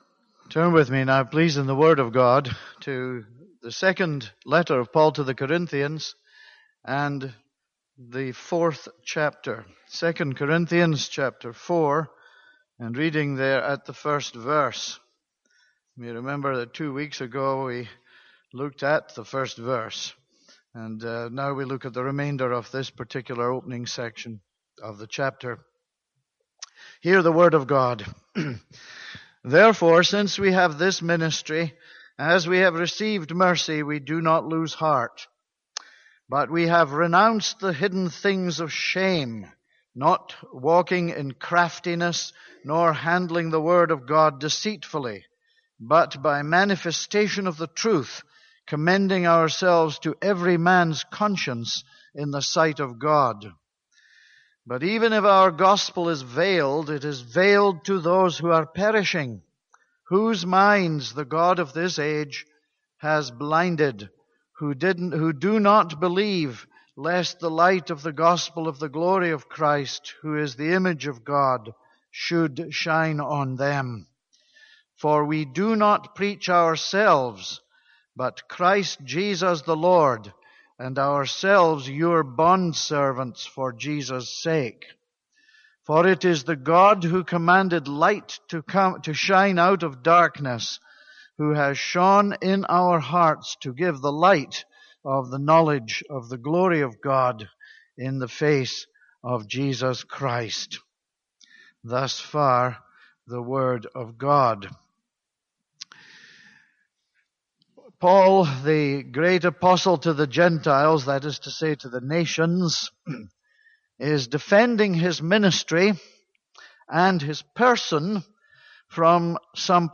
This is a sermon on 2 Corinthians 4:1-6.